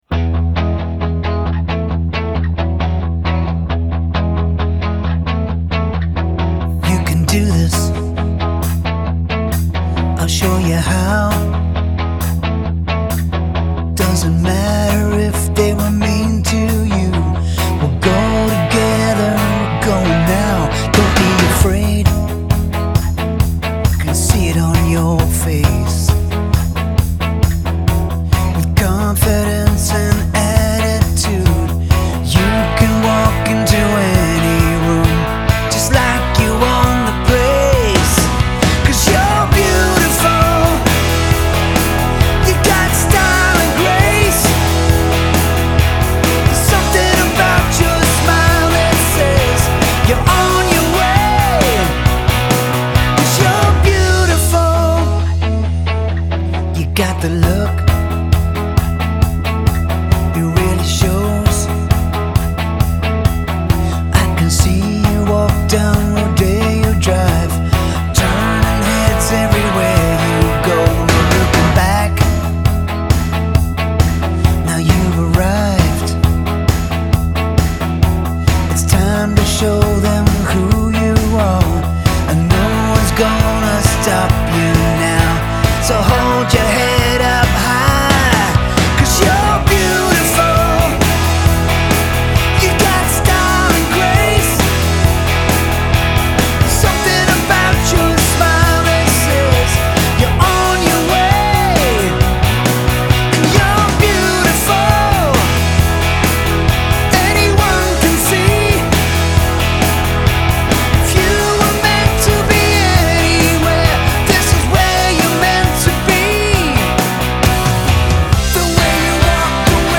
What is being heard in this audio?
Genre : Comédies musicales